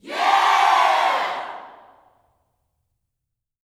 YEAH  02.wav